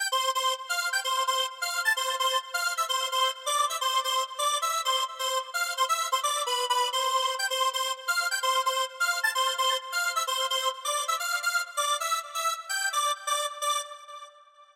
描述：一小包电子循环。
标签： 128 bpm Electronic Loops Synth Loops 2.49 MB wav Key : C
声道立体声